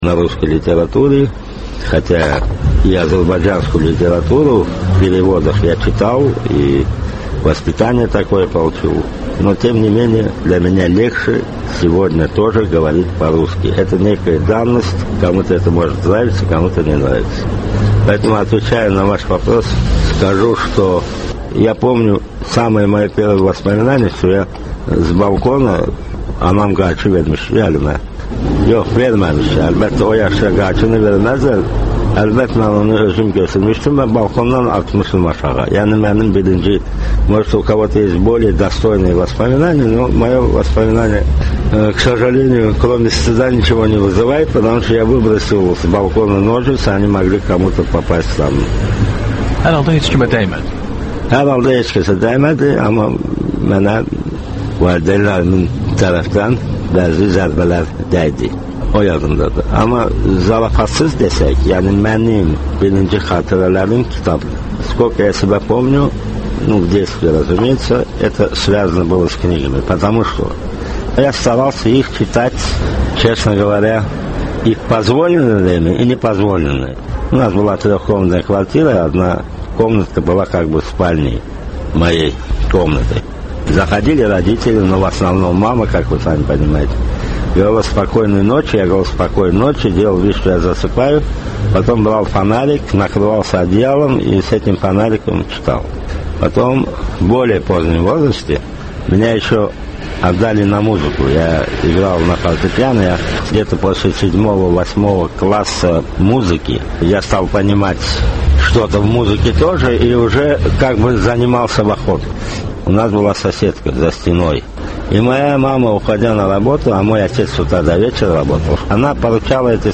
Ölkənin tanınmış simaları ilə söhbət